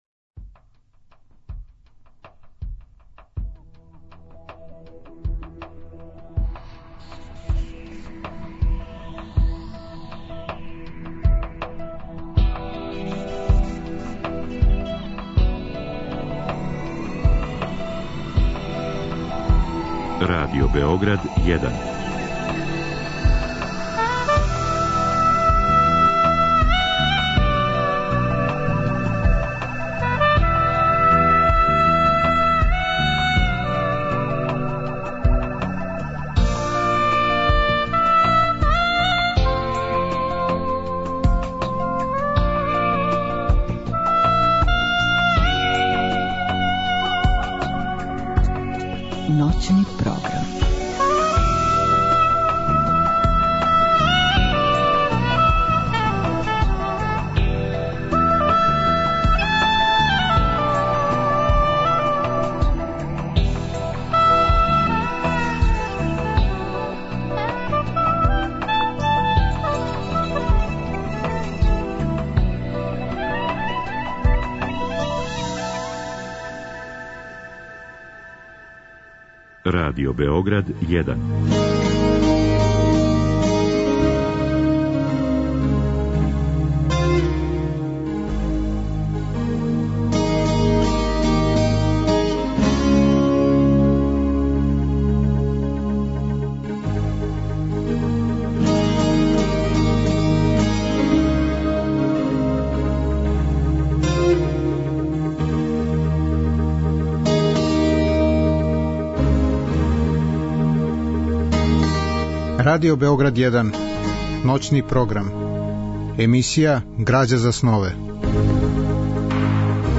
Разговор и добра музика требало би да кроз ову емисију и сами постану грађа за снове.
Драма је реализована 2005. године у продукцији Драмског програма Радио Београда.